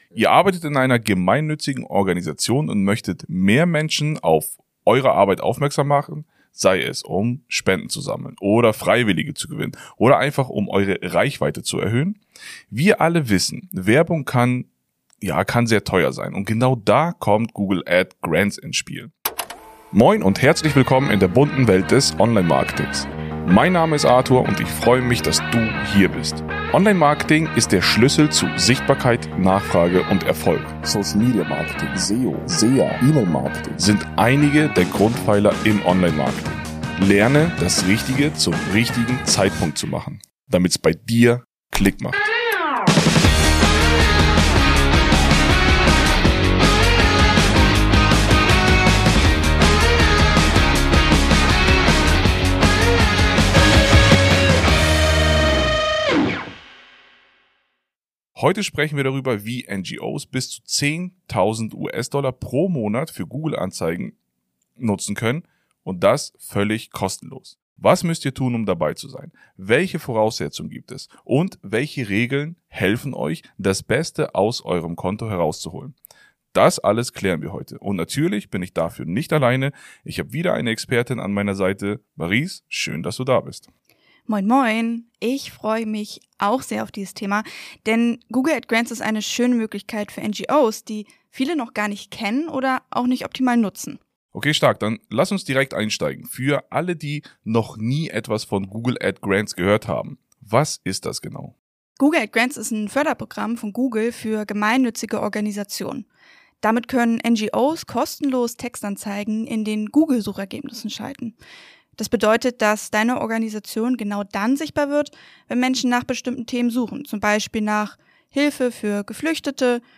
Bleib außerdem dran für kommende Folgen, in der ich dir tiefere Einblicke in spezifische Online Marketing Strategien und Interviews mit weiteren Experten gebe.